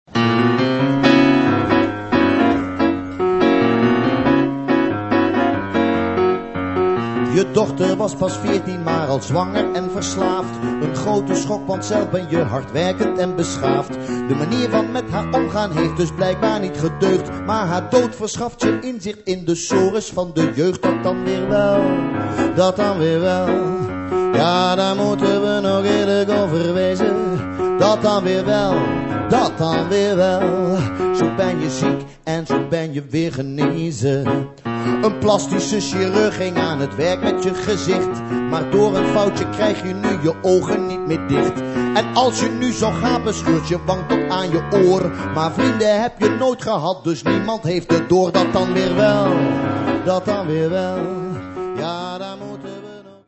Drie keer Hans Teeuwen op het podium.
Luister naar zijn veelzijdigheid, zijn dialect en zijn vermogen om binnen seconden totaal van kleur te verschieten.